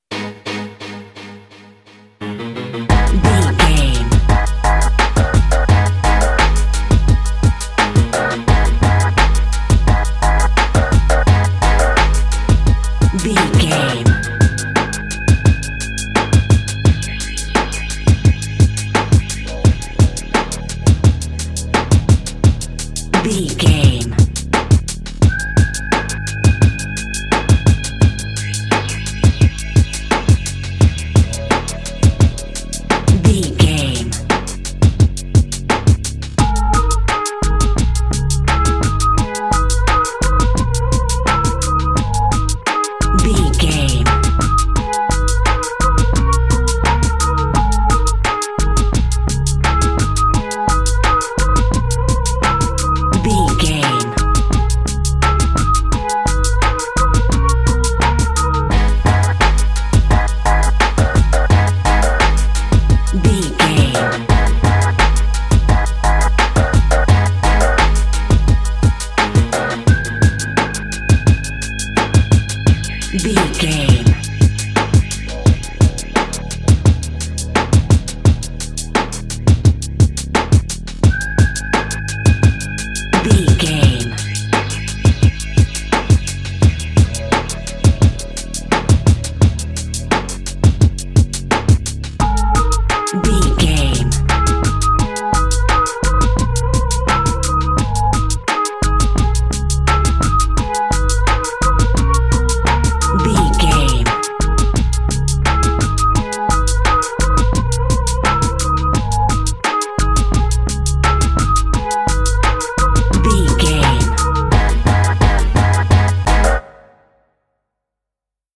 Aeolian/Minor
G#
drum machine
synthesiser